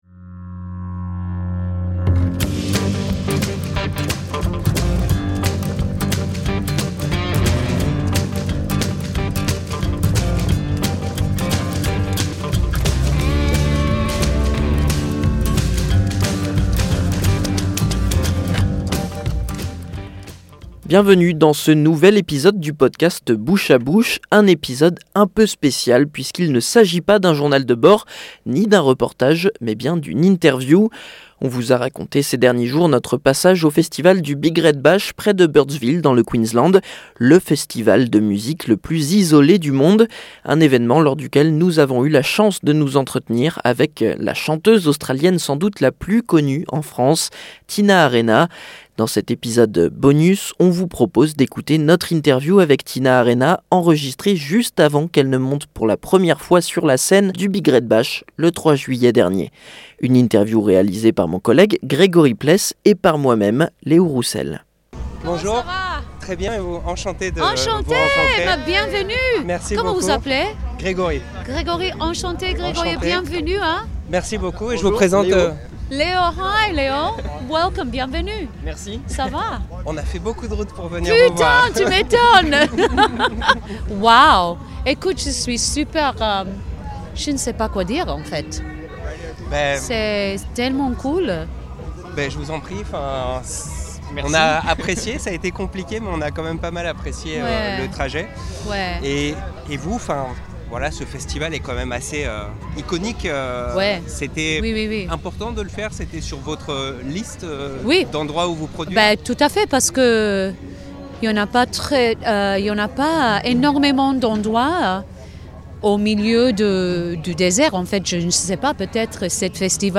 À l'occasion des fêtes de fin d'année, nous vous proposons de redécouvrir notre interview de la chanteuse australienne Tina Arena. Tête d'affiche du festival du Big Red Bash en juillet dernier, elle avait accepté de se livrer au micro du podcast Bouche à Bush avant de monter sur scène. Elle évoque sa présence au festival, ses projets, et son lien avec la France, le tout dans une interview intrégralement en Français.